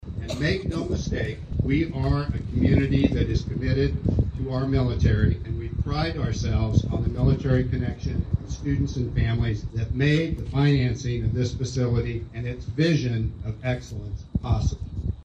Geary County Schools USD 475 hosted a ribbon cutting and dedication ceremony for the 437,000 square foot facility.